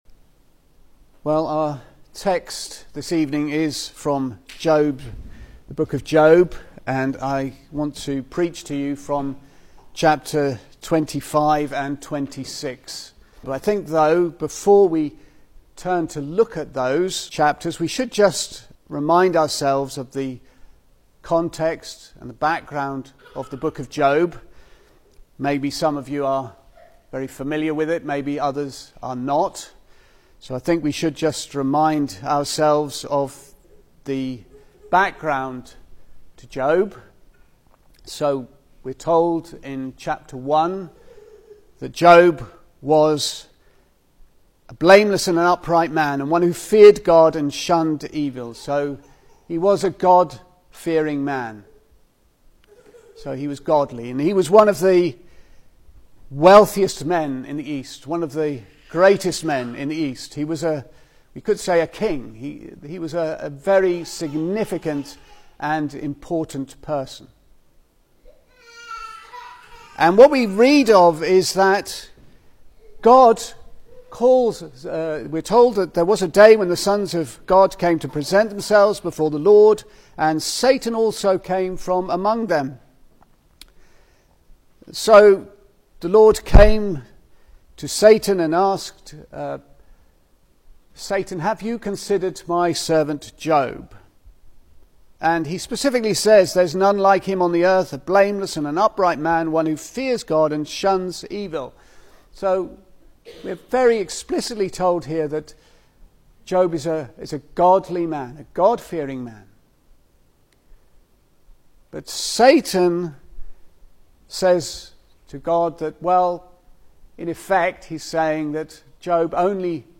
Service Type: Sunday Evening
Series: Single Sermons